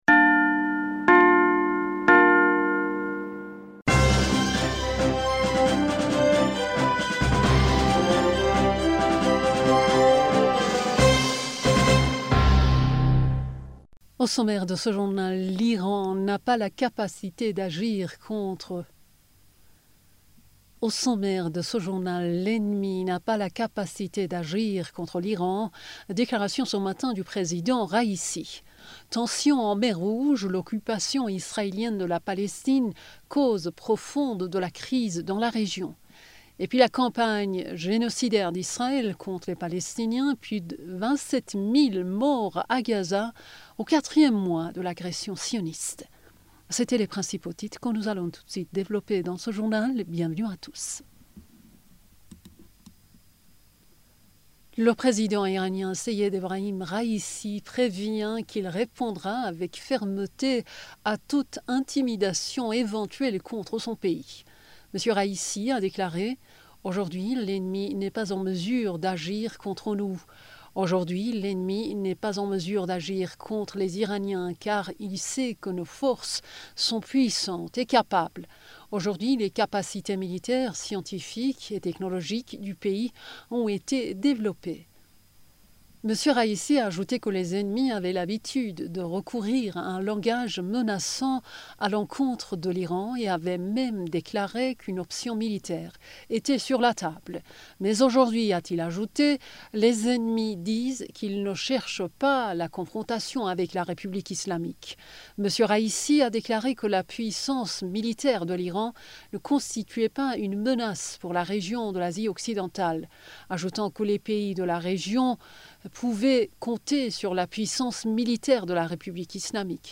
Bulletin d'information du 02 Fevrier 2024